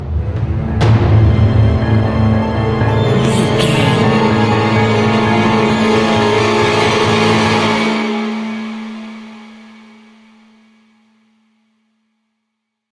Short Build up for a Death Scene.
Atonal
ominous
suspense
eerie
strings
cymbals
gongs
taiko drums
timpani